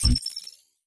Robotic Game Notification 13.wav